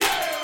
SouthSide Chant (6).wav